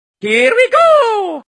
Here We Go! Mario Sound Effect
Category: Sound FX   Right: Personal